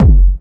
GS Phat Kicks 008.wav